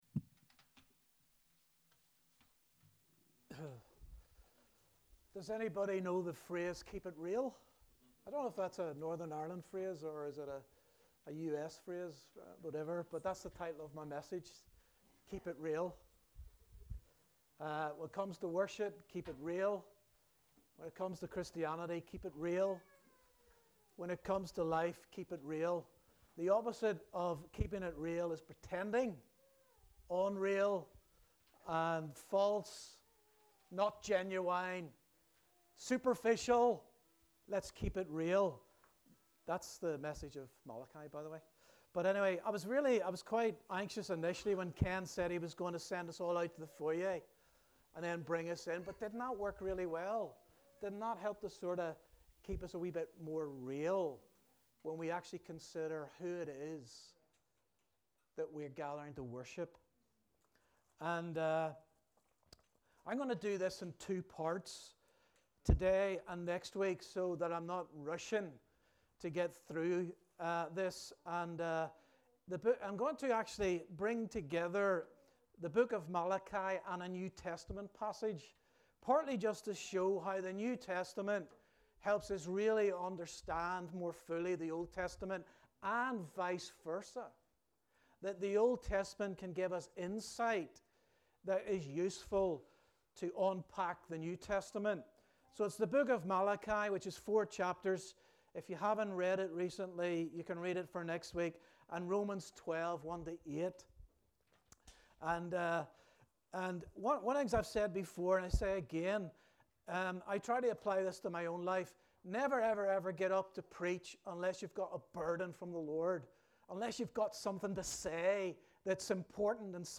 A message from the series "All Messages."